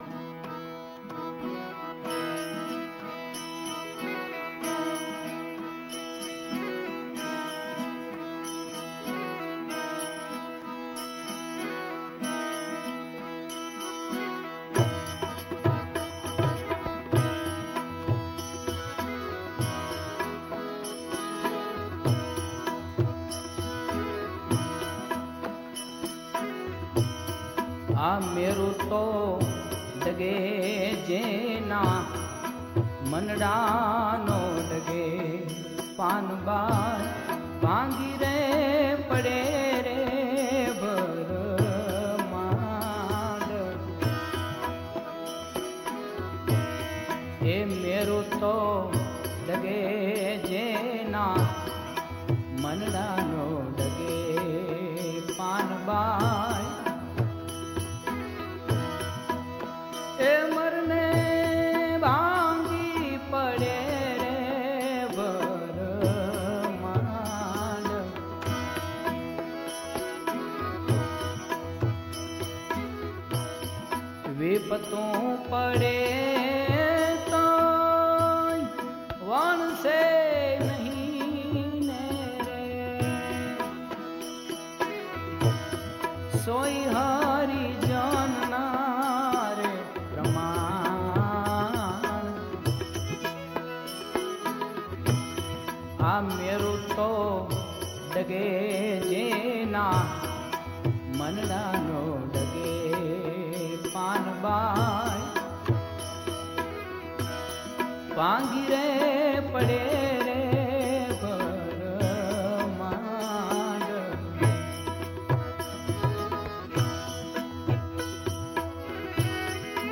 ગીત સંગીત ભજનાવલી - Bhajans